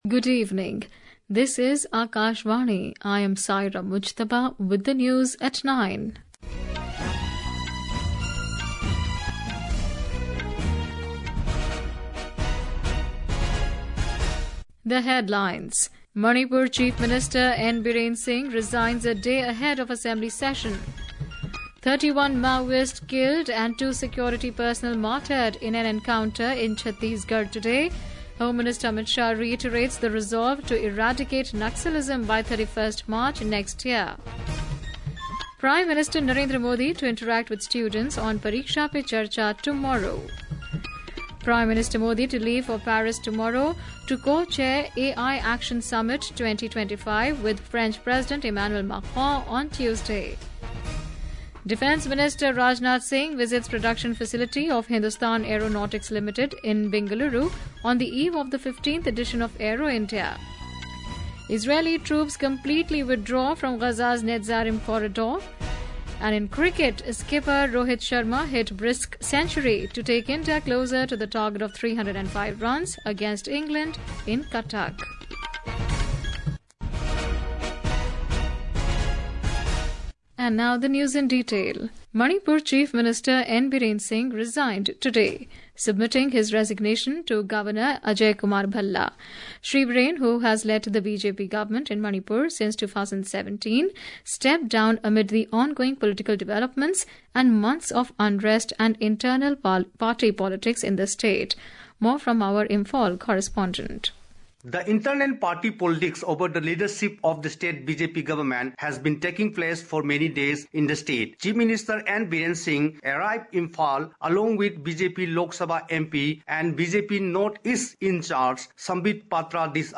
Evening News | English